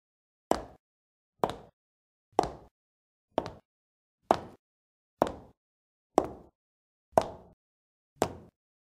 دانلود آهنگ کفش هنگام راه رفتن 2 از افکت صوتی انسان و موجودات زنده
دانلود صدای کفش هنگام راه رفتن 2 از ساعد نیوز با لینک مستقیم و کیفیت بالا
جلوه های صوتی